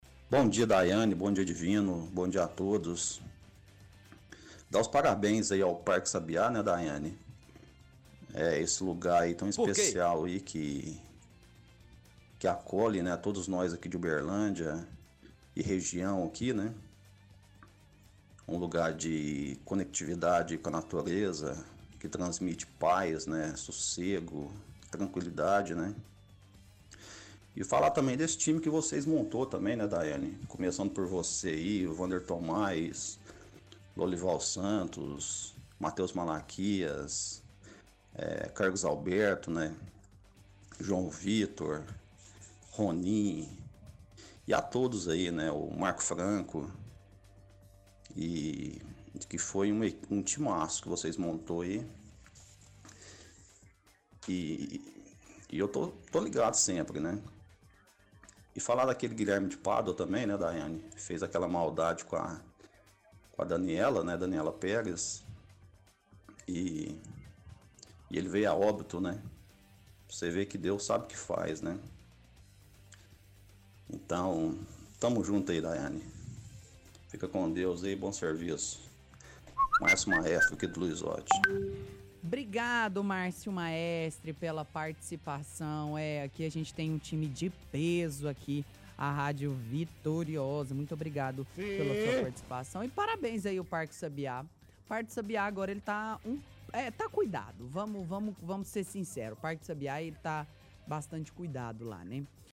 – Ouvinte parabeniza o Parque pelo aniversário, elogiando o local.